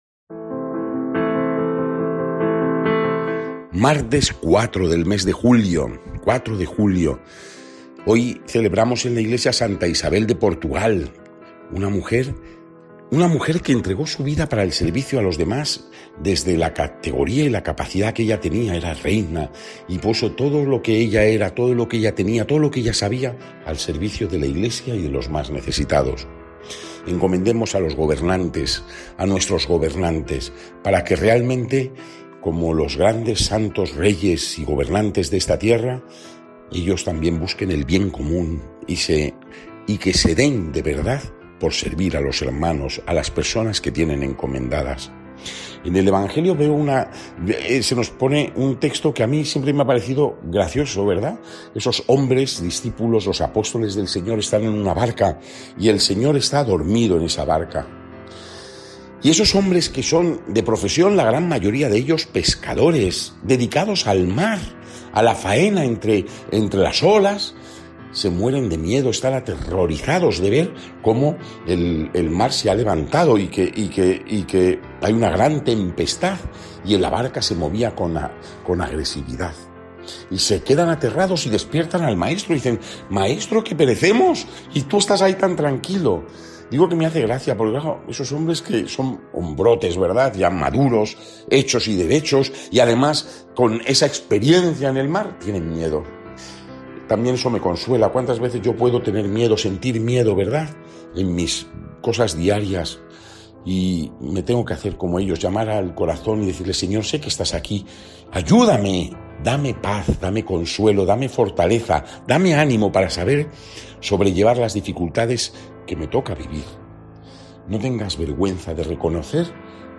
Evangelio del día
Lectura del santo evangelio según san Mateo 8,23-27